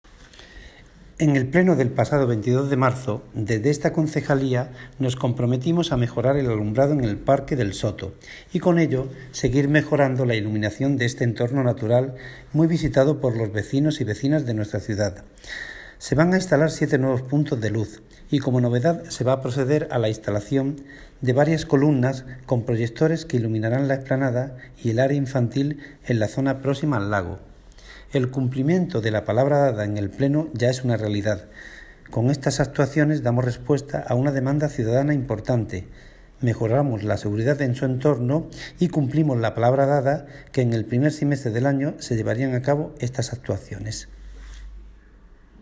Audio - Agustín Martín (Concejal de Deportes, Obras, Infraestructuras y Mantenimiento de vías públicas)